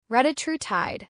Retatrutide (reh-TA-troo-tide)
retatrutide-pronounciation.mp3